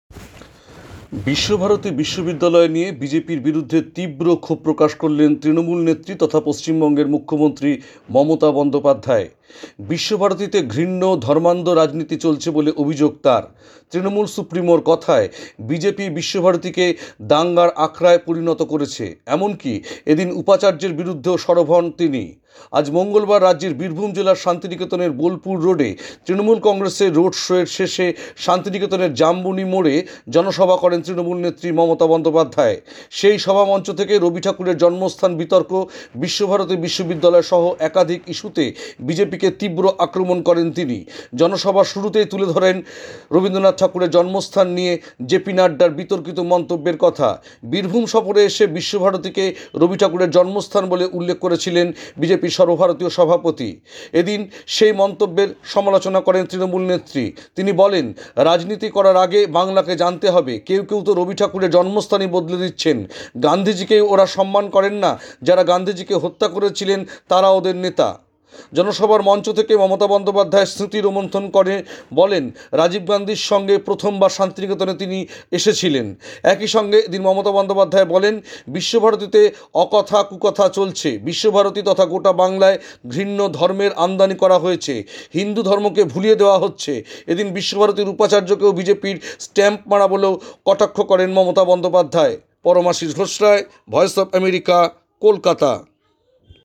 আজ মঙ্গলবার রাজ্যের বীরভূম জেলার শান্তিনিকেতনে বোলপুর রোডে তৃণমূল কংগ্রেসের শোয়ের শেষে শান্তিনিকেতনের জামবুনি মোড়ে জনসভা করেন তৃণমূল নেত্রী মমতা বন্দ্যোপাধ্যায়। সেই সভামঞ্চ থেকে রবি ঠাকুরের জন্মস্থান বিতর্ক, বিশ্বভারতী বিশ্ববিদ্যালয়-সহ একাধিক ইস্যুতে বিজেপিকে তীব্র আক্রমণ করেন তিনি।